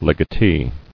[leg·a·tee]